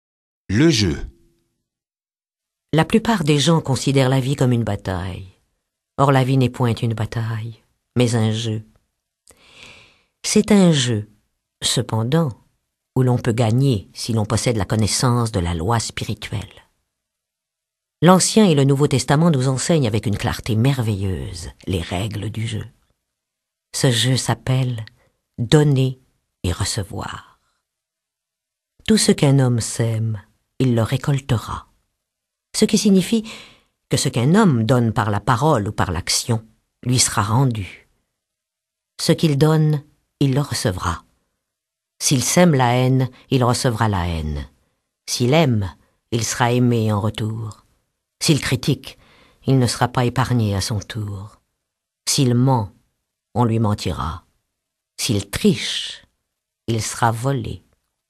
Extrait gratuit - Le Jeu de la Vie et comment le jouer de Florence Scovel Shinn